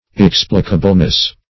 \Ex"pli*ca*ble*ness\